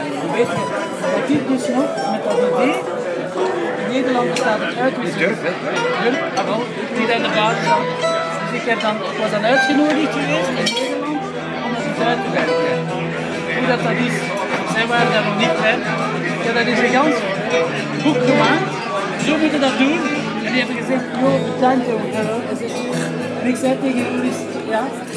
Tuning up at Johnnie Fox's